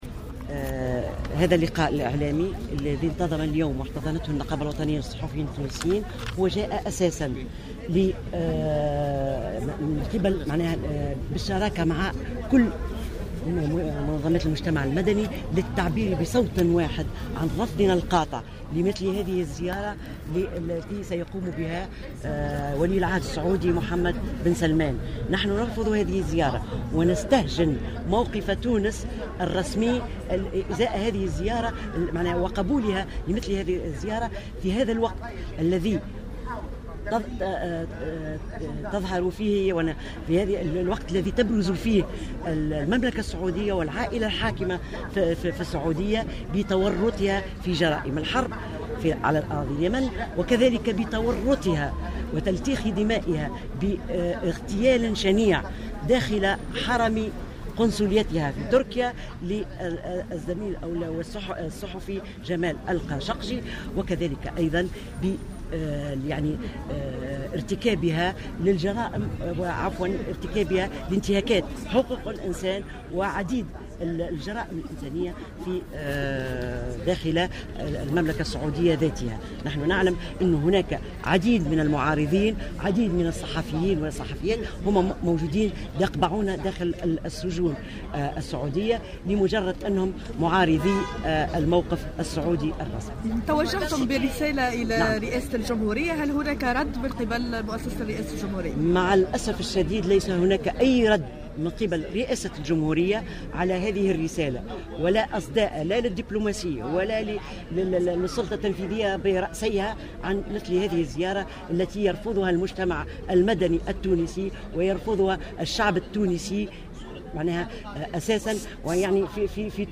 أعلنت مجموعة من منظمات المجتمع المدني في مؤتمر صحفي اليوم بمقر نقابة الصحفيين حول الزيارة المرتقبة لولي العهد السعودي إلى تونس رفضهم التام لإستقباله بسبب تورطه في قضايا انسانية أساسية وهي الحرب في اليمن و اغتيال الصحفي جمال خشقجي.